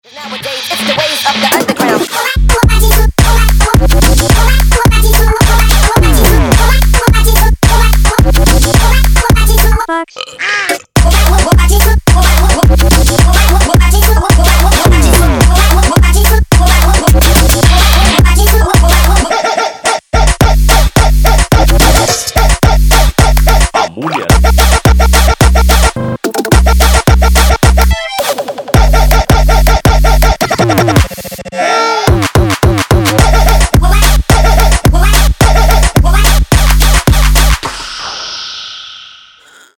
• Качество: 192, Stereo
Trap
Moombahton